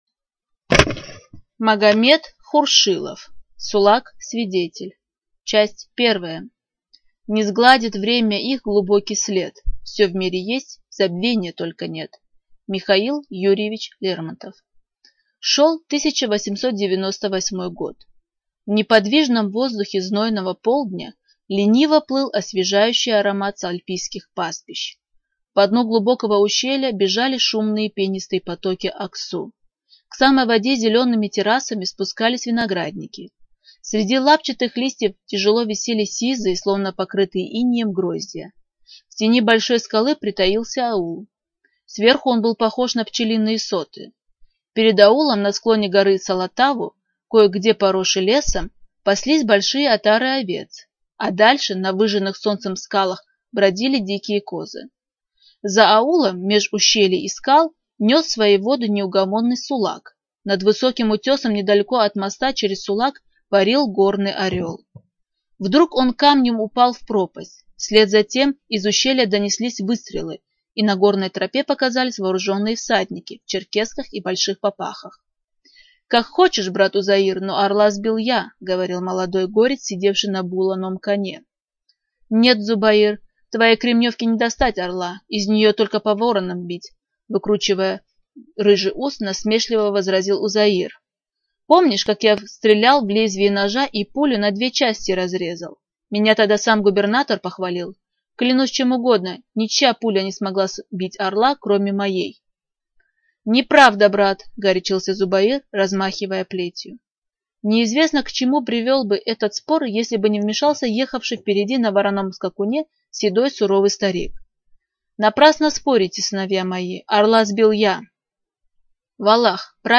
Студия звукозаписиДагестанская республиканская библиотека для слепых